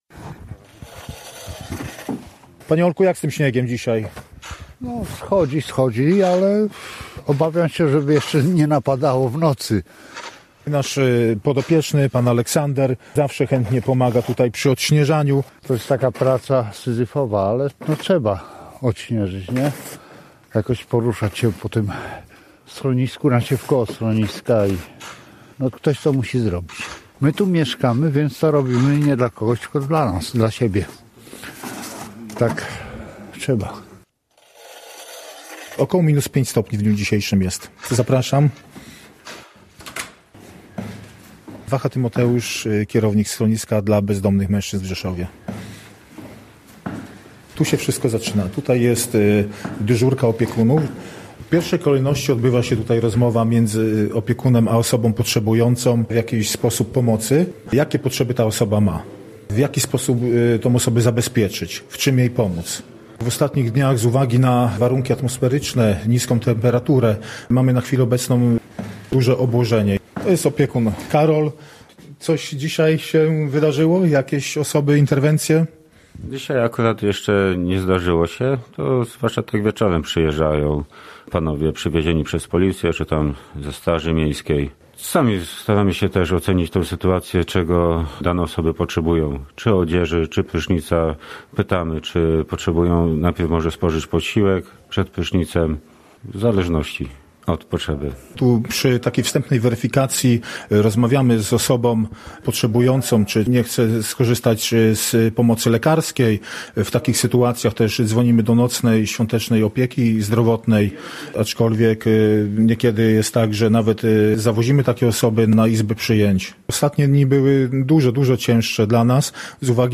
„Ich miejsce na ziemi” to zapis wizyty w schronisku dla bezdomnych mężczyzn w Rzeszowie oraz działającej tam noclegowni.
14_01_Reportaz_Schronisko.mp3